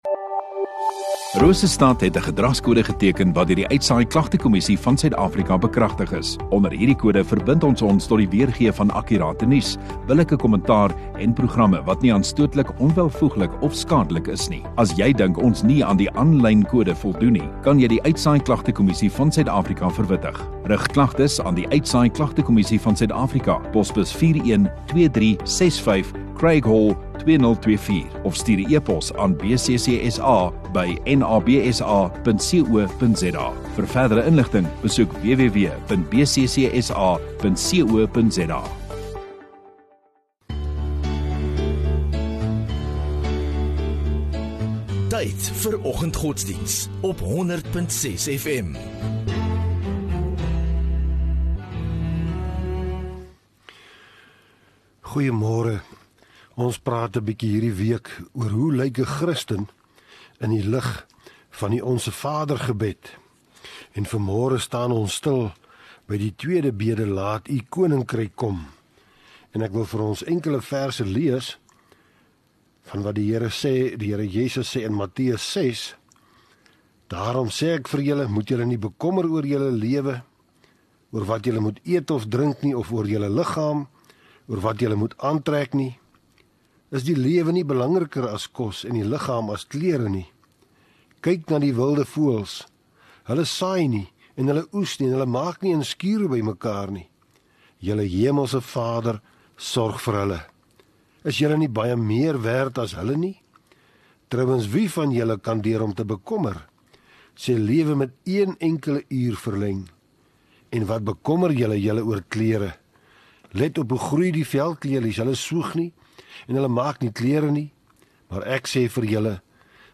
24 Sep Woensdag Oggenddiens